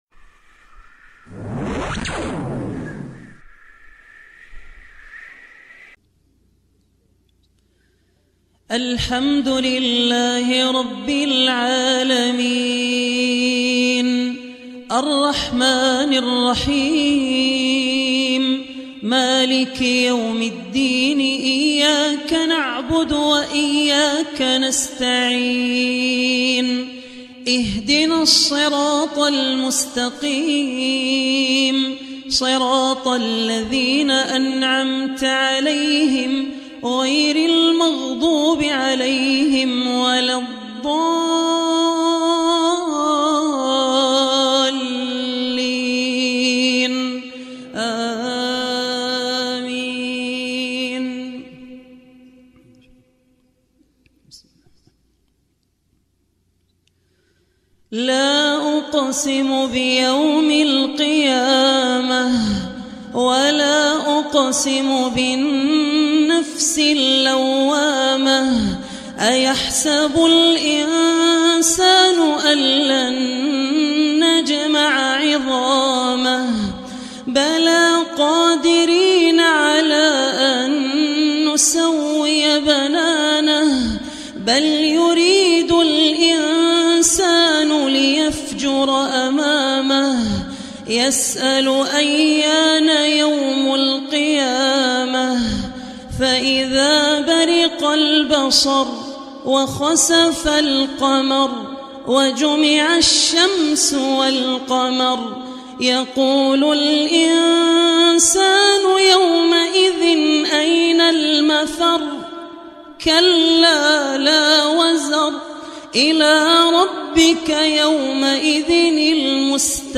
Surah Qiyamah Recitation by Abdur Rehman Al Ossi
Surah Qiyamah, listen online mp3 tilawat / recitation in the voice of Sheikh Abdur Rehman Al Ossi.